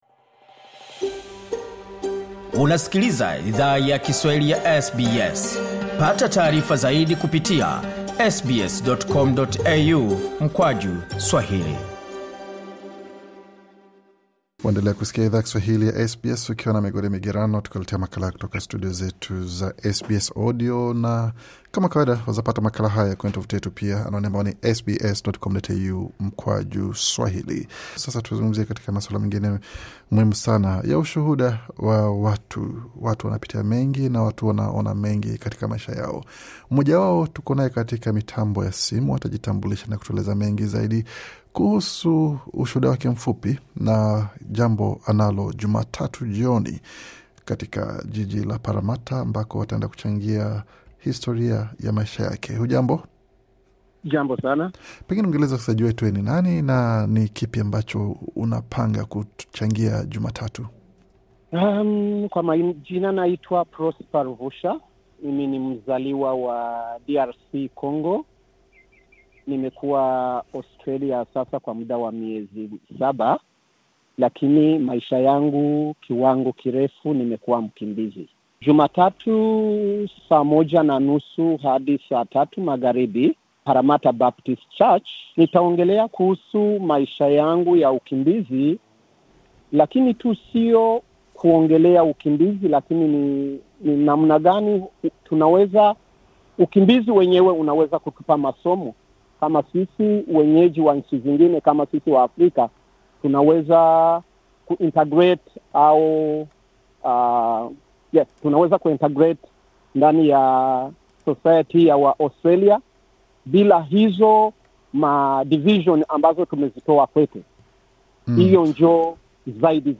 Anwani ya tukio ni: 5 Parramatta Square, Parramatta, NSW 2150 Bonyeza hapo juu kwa mahojiano kamili.